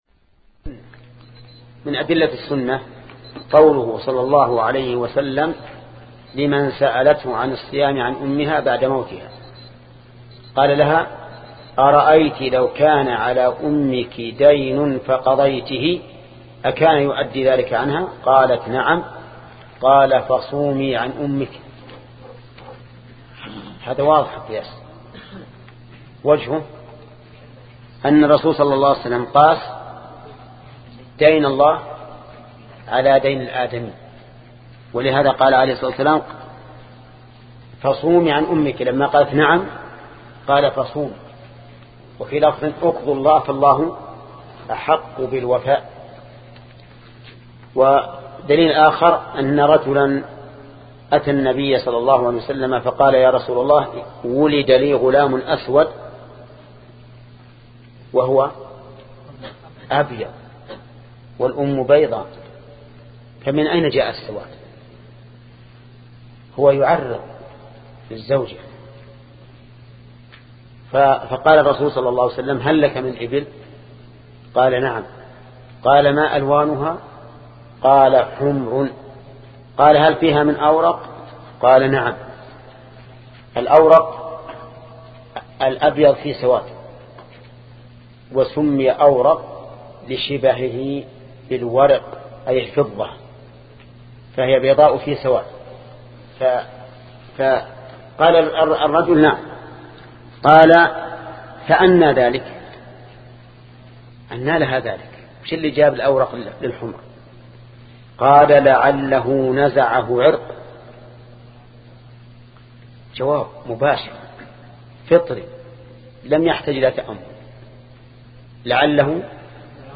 شبكة المعرفة الإسلامية | الدروس | الأصول من علم الأصول 18 |محمد بن صالح العثيمين